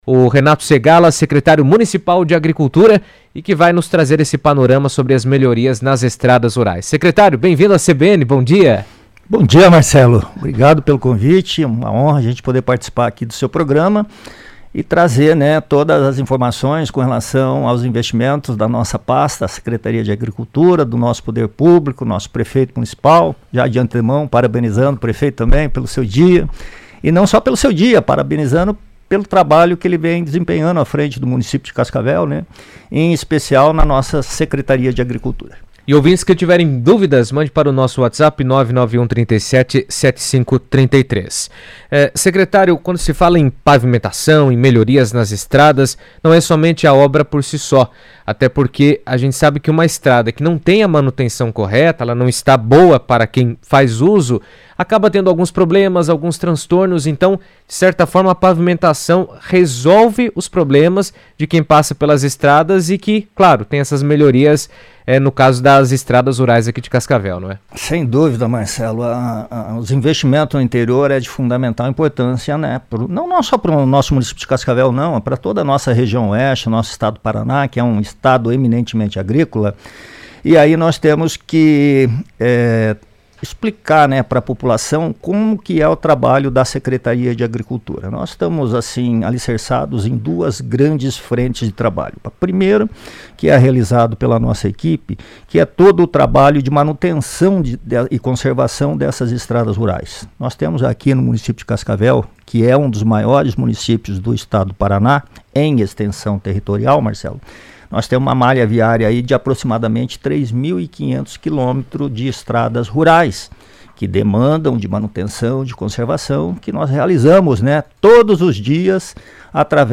O governo municipal tem investido na pavimentação e manutenção de estradas rurais, fortalecendo o acesso da população da zona rural às cidades e garantindo o escoamento da produção agrícola. Renato Segalla, secretário municipal de Agricultura, comentou na CBN que esses investimentos melhoram a logística, aumentam a segurança no trânsito e contribuem para o desenvolvimento econômico da região.